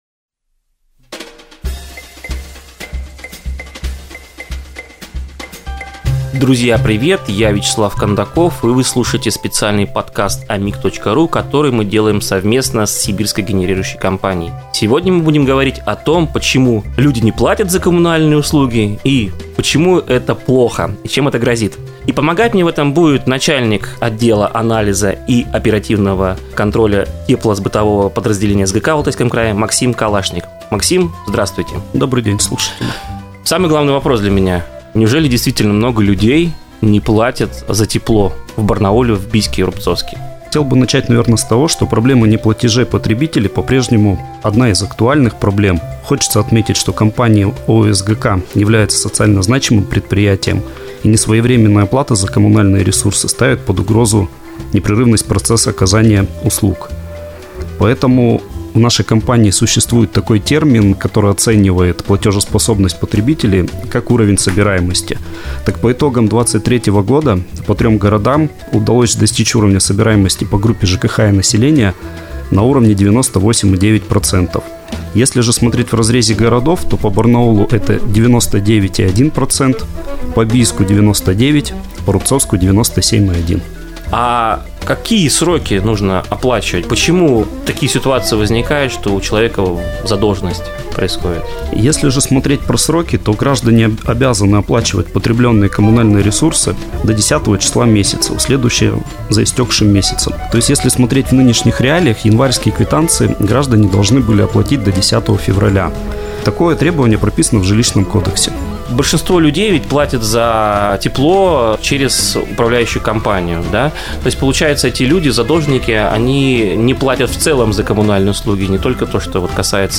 У микрофона: